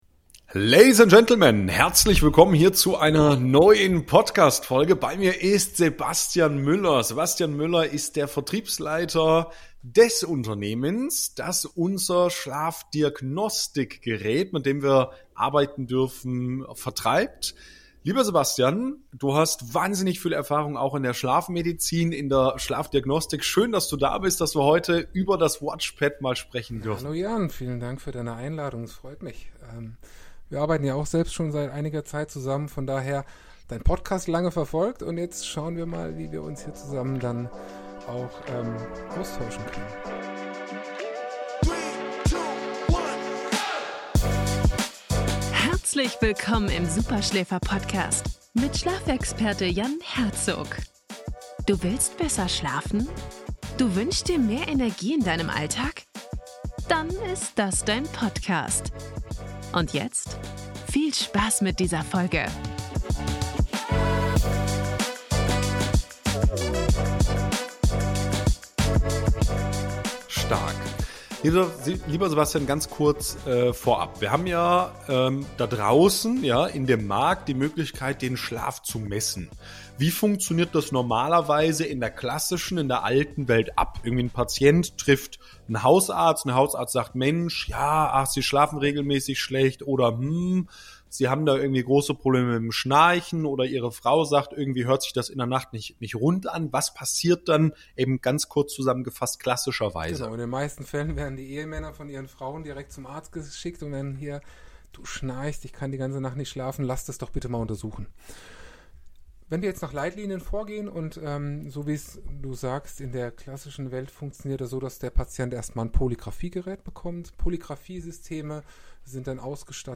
#287 Professionelle Schlafmessung für zu Hause: WatchPAT ONE Schlafdiagnostik | Interview